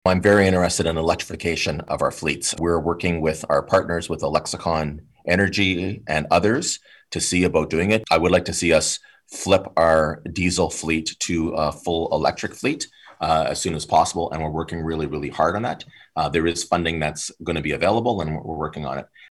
Following Monday afternoon’s meeting, Mayor Mitch Panciuk told reporters he hopes the declaration can help the city move forward on at least one major eco-friendly project in the near future.